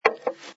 sfx_put_down_glass08.wav